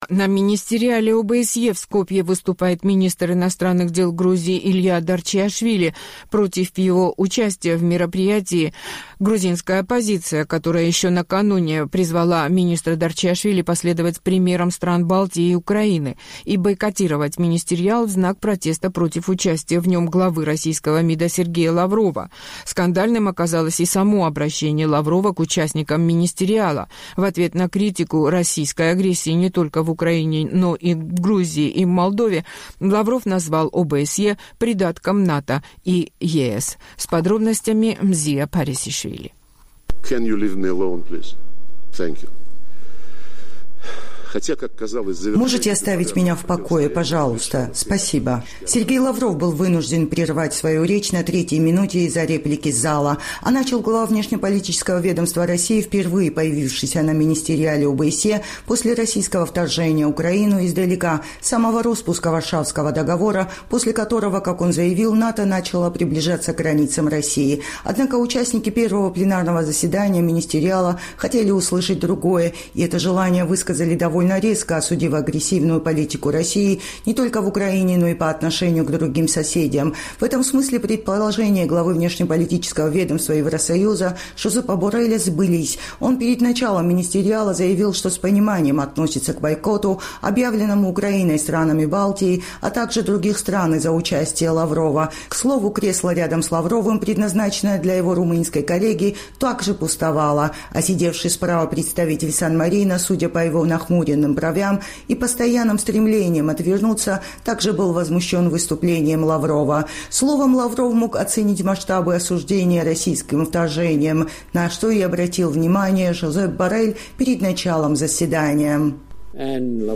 «Можете оставить меня в покое, пожалуйста? Спасибо»,Сергей Лавров был вынужден прервать свою речь на третьей минуте из-за реплики из зала.